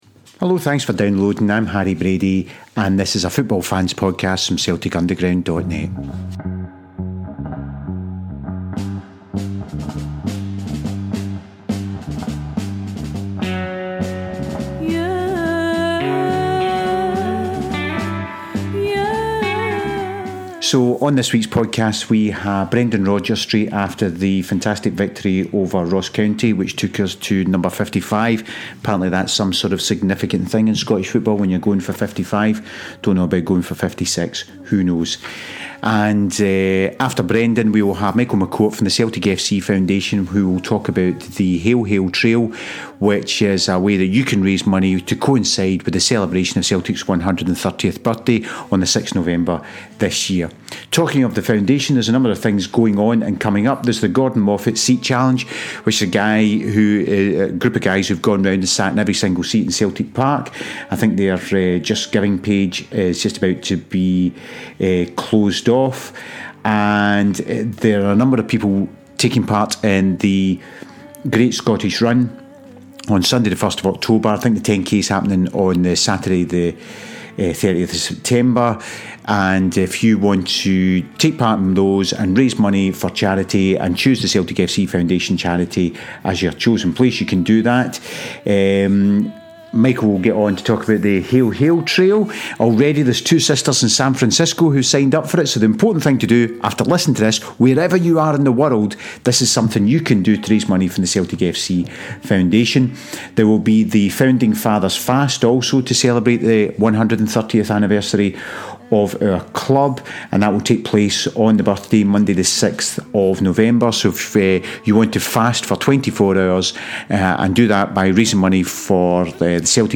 press conference following the Ross County game